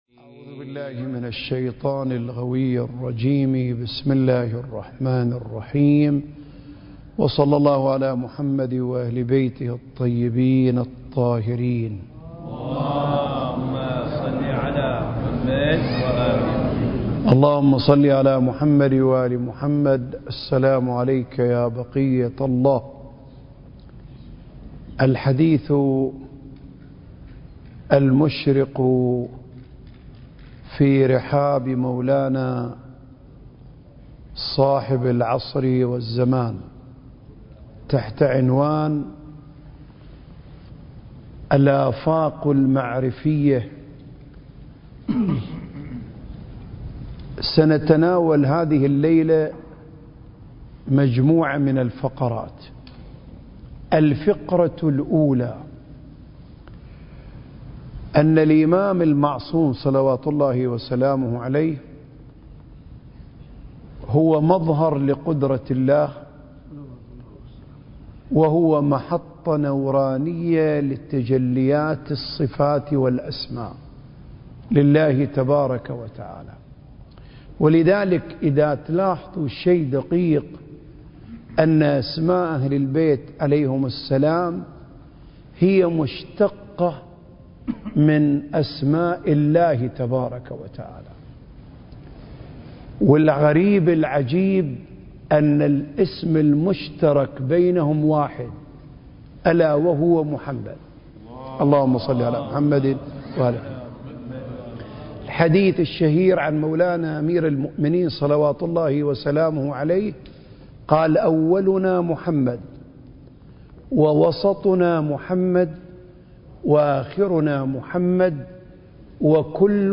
سلسلة محاضرات: آفاق المعرفة المهدوية (3) المكان: الأوقاف الجعفرية بالشارقة التاريخ: 2023